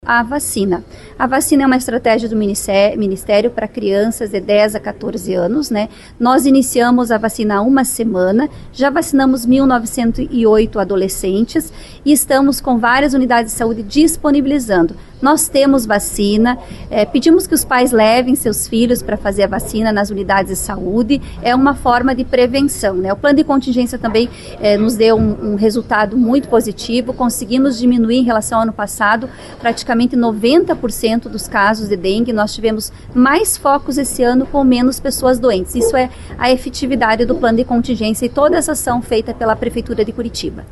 A informação foi divulgada por meio da responsável pela pasta, Tatiane Filipak, durante a audiência de prestação de contas da Saúde relativa ao primeiro quadrimestre deste ano. Ela falou sobre o cenário da imunização e a respeito dos casos da doença na cidade.